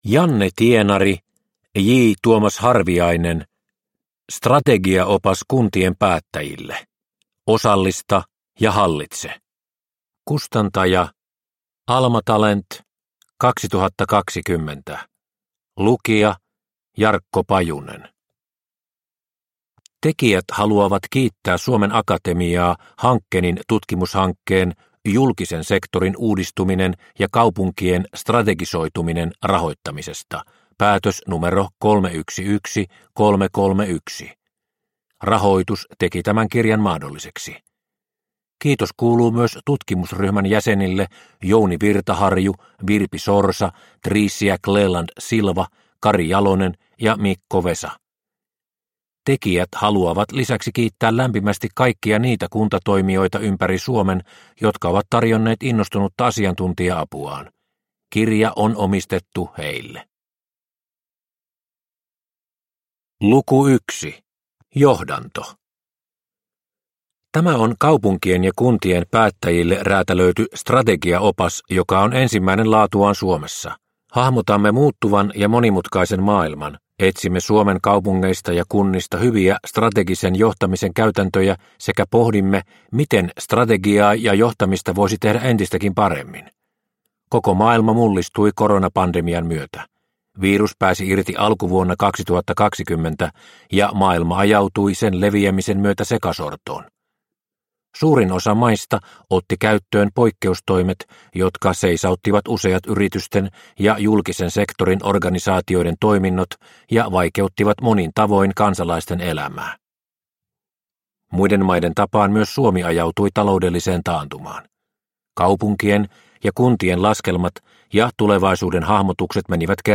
Strategiaopas kuntien päättäjille – Ljudbok – Laddas ner